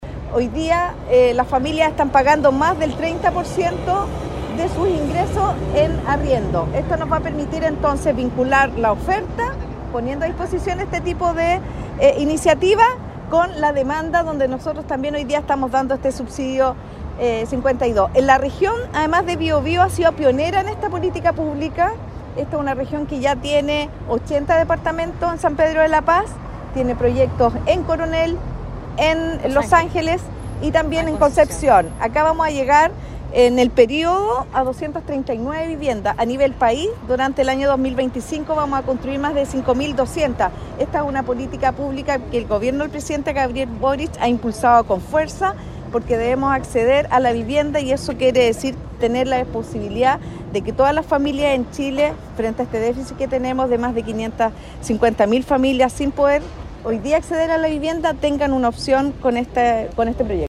“Hoy día las familias están pagando más del 30% de sus ingresos en arriendo”, comentó la subsecretaria de Vivienda, Gabriela Elgueta, sobre cómo esta política pública viene a presentar una nueva propuesta de oferta y demanda en arriendos en Chile.
Arriendo-Justo-1-Subsecretaria.mp3